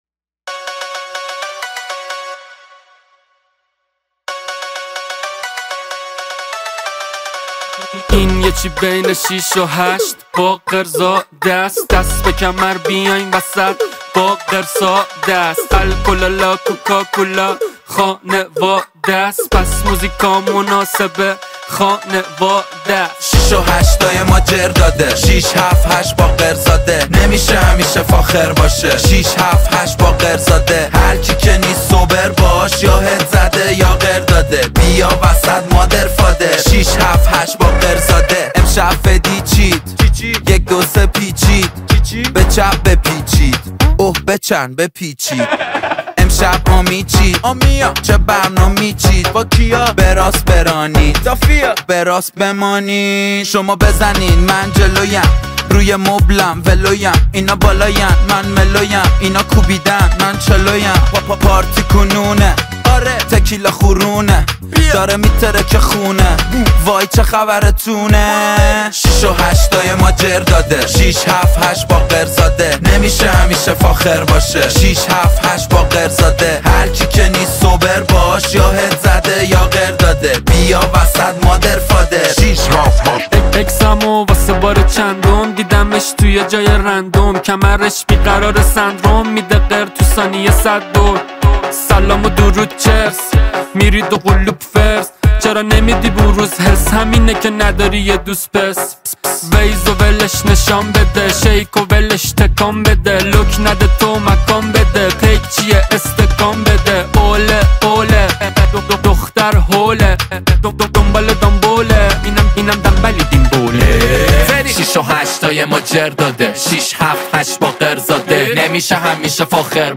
رپ و هیپ‌هاپ فارسی